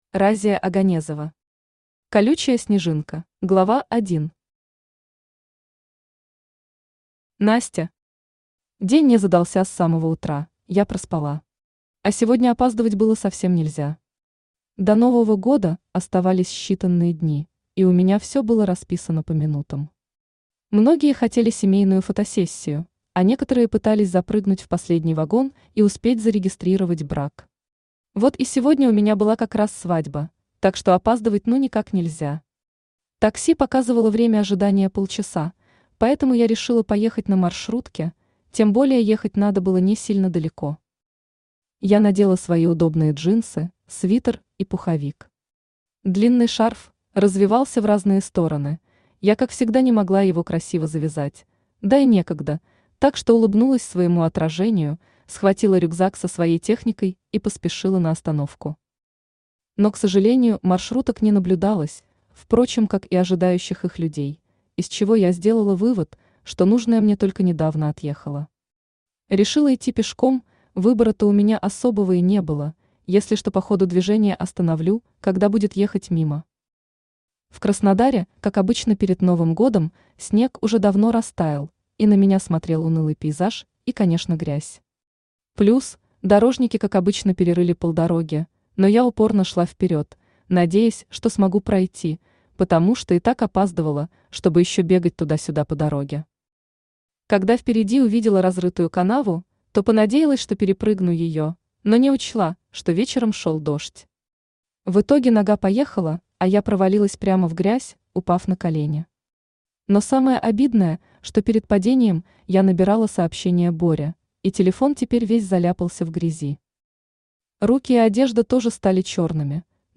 Аудиокнига Колючая Снежинка | Библиотека аудиокниг
Aудиокнига Колючая Снежинка Автор Разия Оганезова Читает аудиокнигу Авточтец ЛитРес.